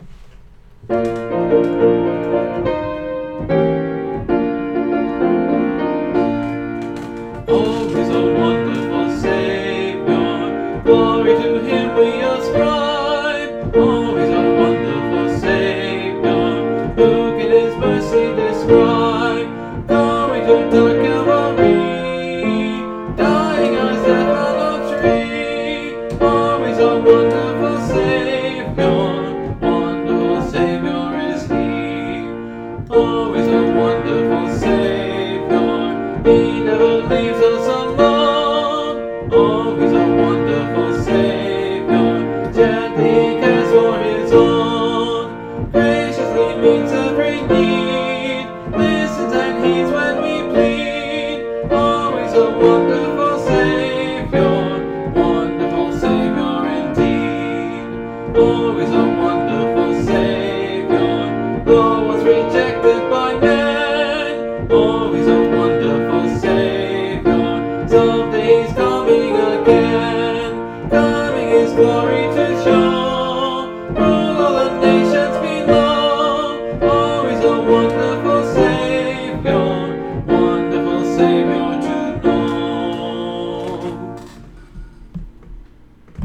(Part of a series singing through the hymnbook I grew up with: Great Hymns of the Faith)
It actually isn’t bad, though the melody on the last line comes out a little flat.